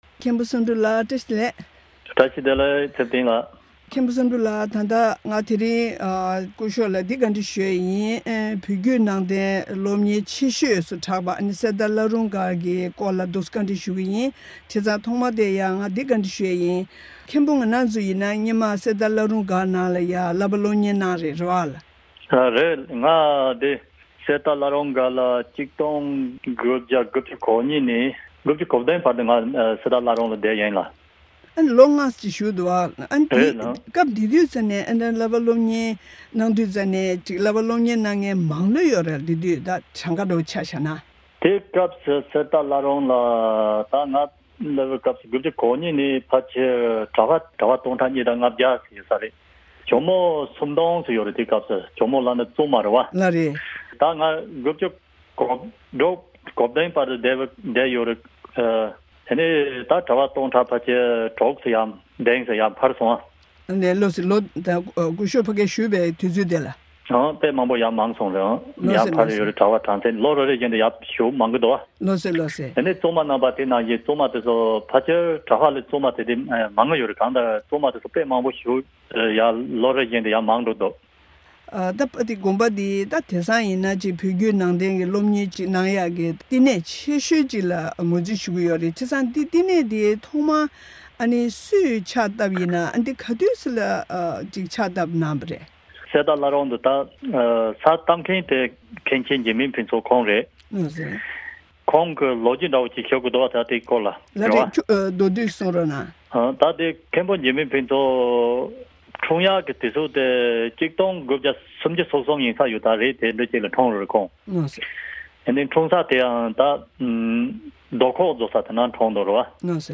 གནས་འདྲི་ཞུས་པའི་དུམ་བུ་དང་པོ་དེ་གསན་རོགས་གནང་།